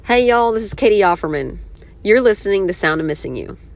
LINER